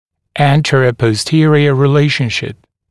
[ˌæntərəpɔs’tɪərɪə rɪ’leɪʃnʃɪp][ˌэнтэрэпос’тиэриэ ри’лэйшншип]соотношение в переднезаднем направлении